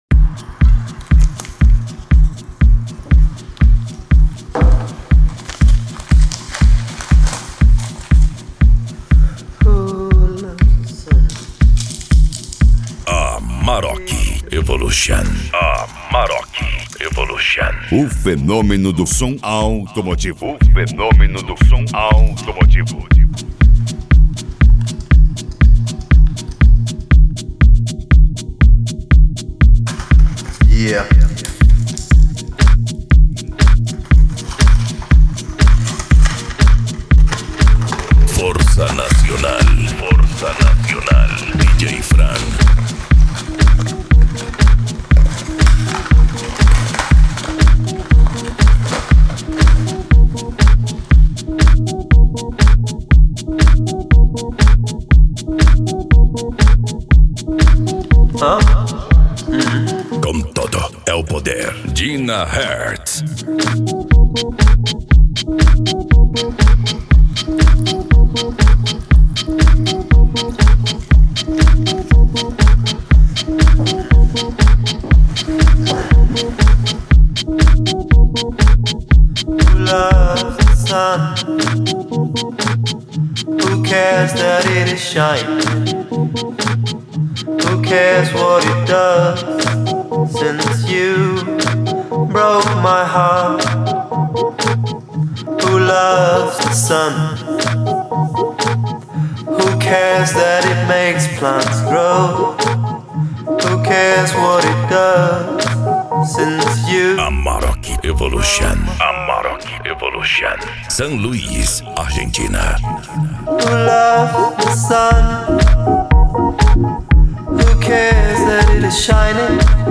Funk
Remix